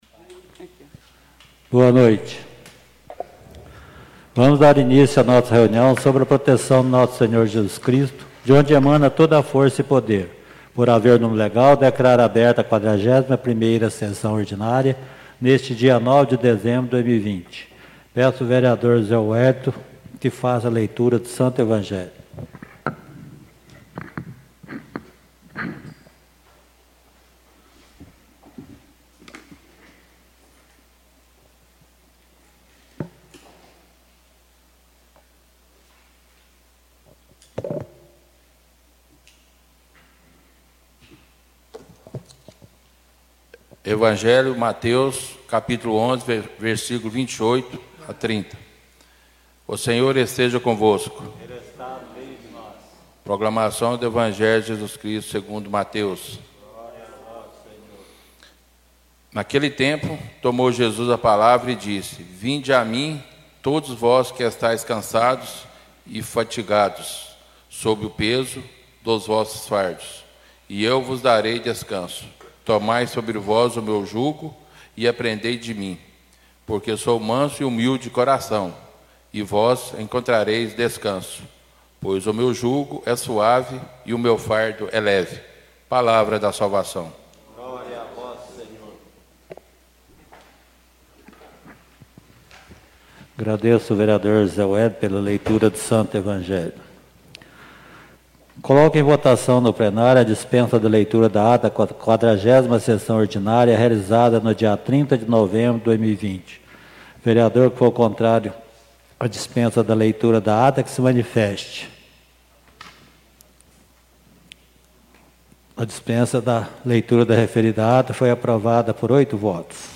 Vamos dar início a nossa reunião, SOB A PROTEÇÃO DO NOSSO SENHOR JESUS CRISTO, DE ONDE EMANA TODA FORÇA E PODER, por haver número legal, declaro aberta a 40ª Sessão Ordinária, neste dia 09 de dezembro de 2020.
Tipo de Sessão: Ordinária